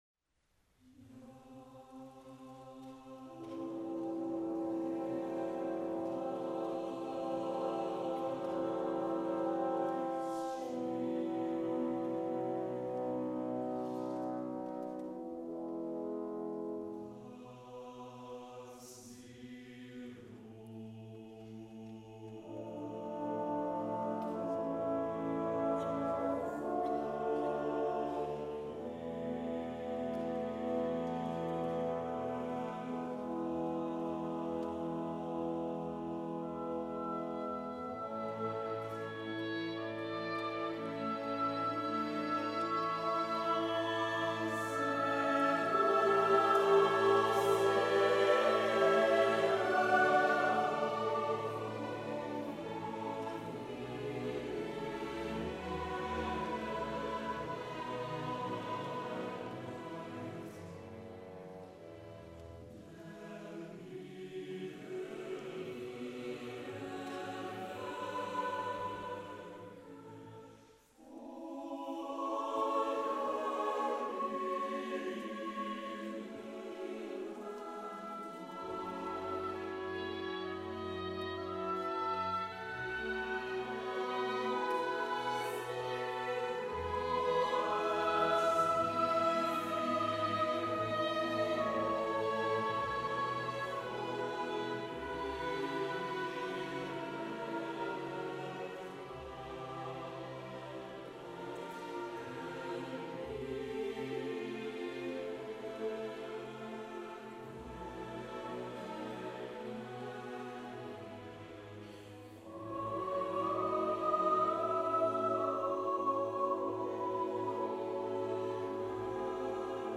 Ez a középrésze a tételnek, a visszatérésben a tenor énekli a témát, majd egyszer csak történik valami: